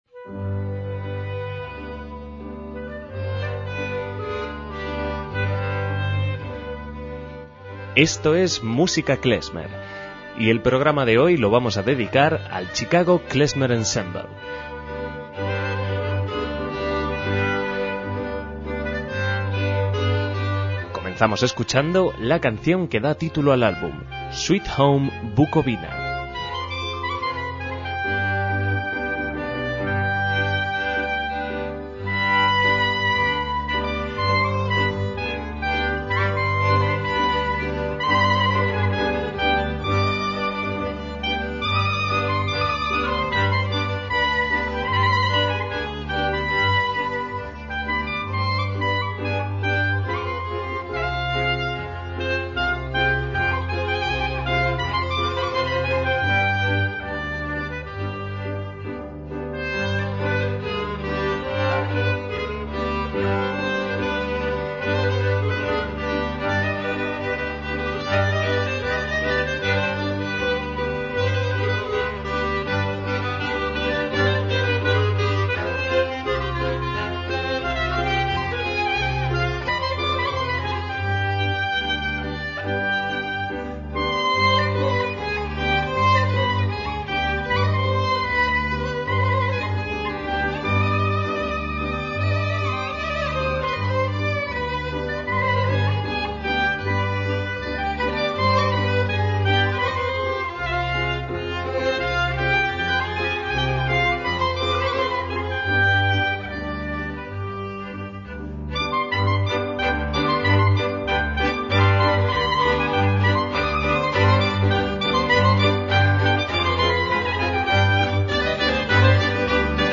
MÚSICA KLEZMER
también en tsimbl, piano y clarinet